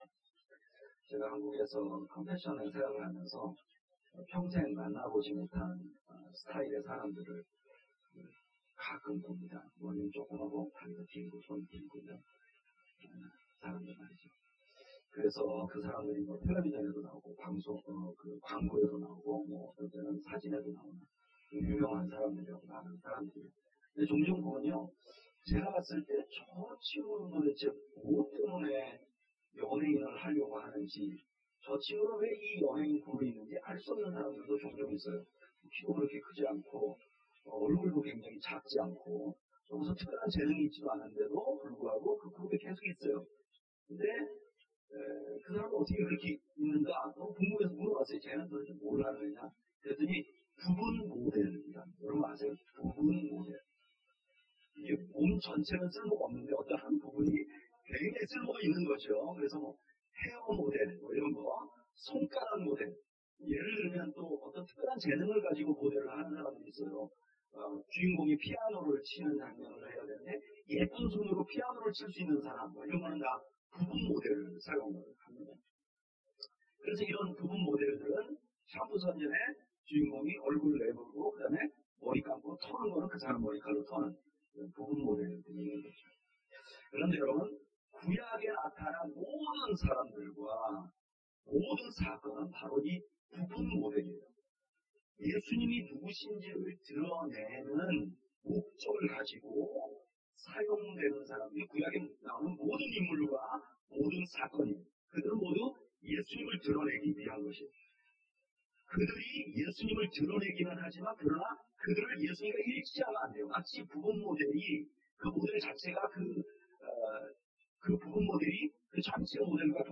주일설교 - The Way Church